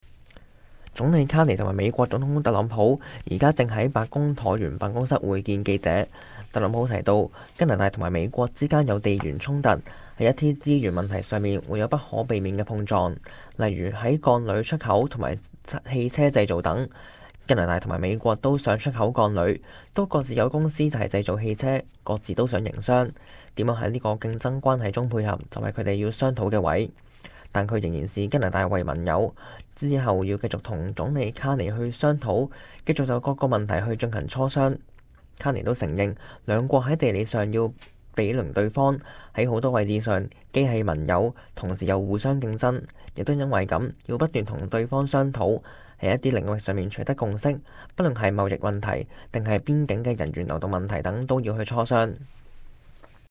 總理卡尼與特朗普於美國白宮見記者
總理卡尼與美國總統特朗普會面現正在白宮拖圓辦公室見記者，特朗普提到加拿大及美國之間有地緣衝突，所以在一些資源問題上會有不可避免的碰撞。